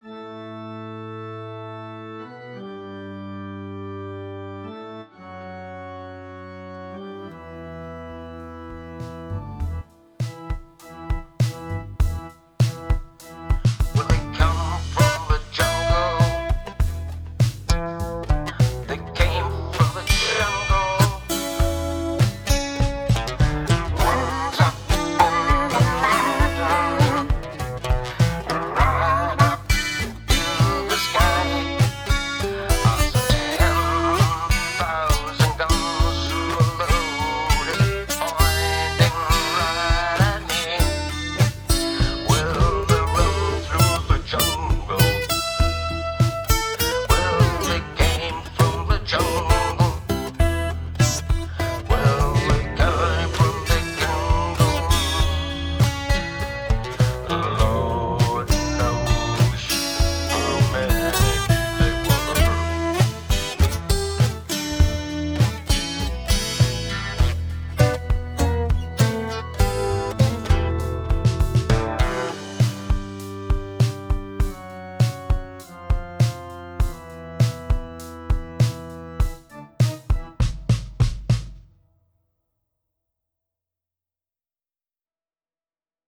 Nedladdning av experimentmusik
1:: Basrik musikanläggning med HÖG volym och få grannar